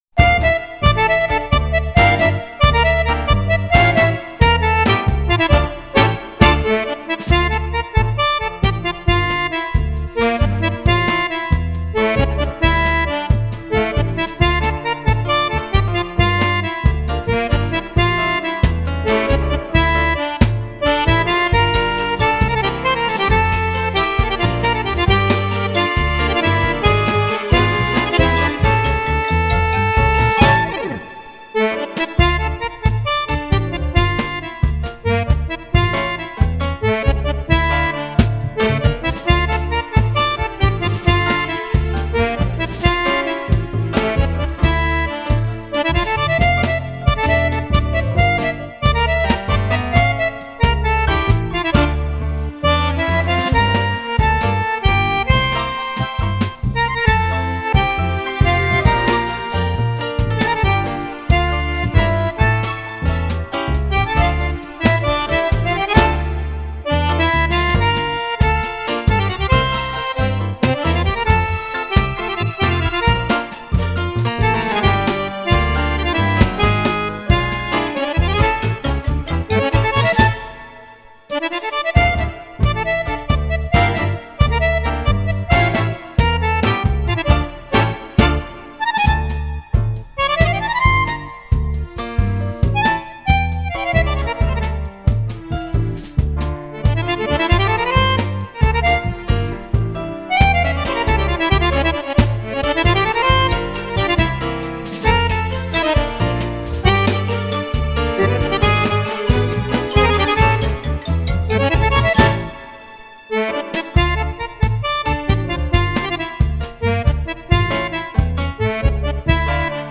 DVD regroupant plusieurs accordéonistes: